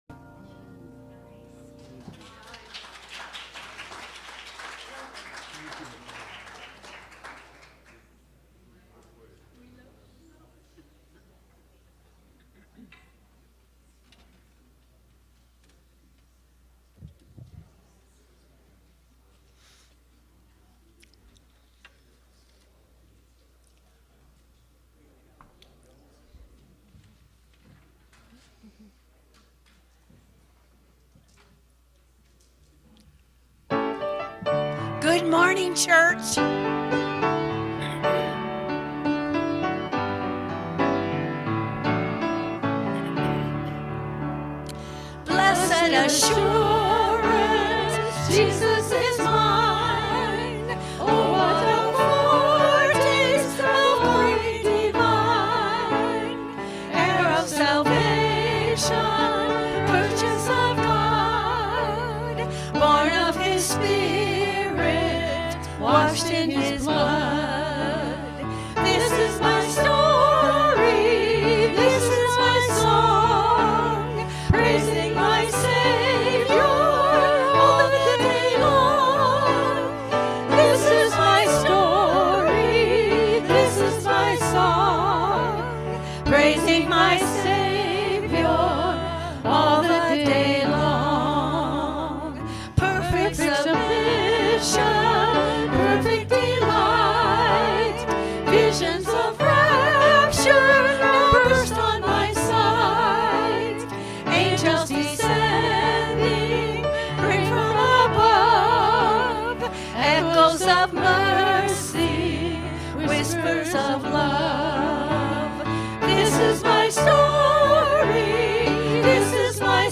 Worship-October-6-2024-Voice-Only.mp3